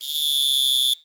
cricket_chirping_solo_01.wav